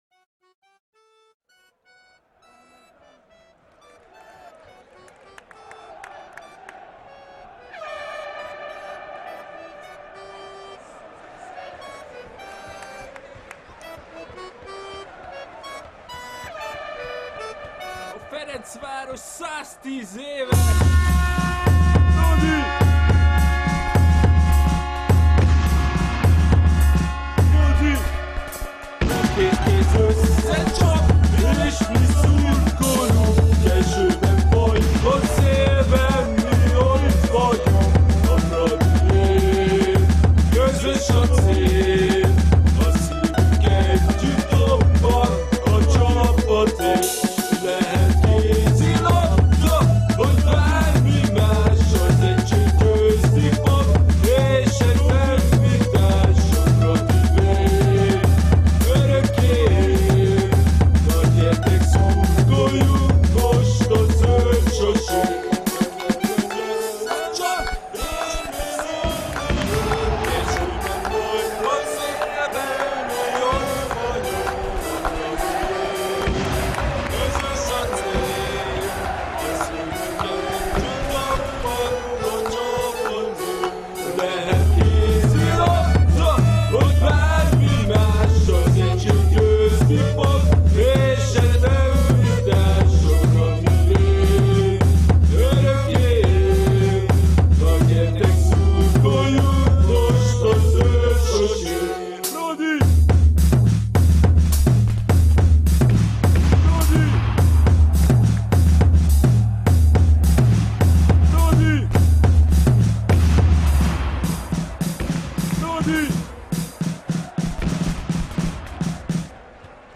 Fradi induló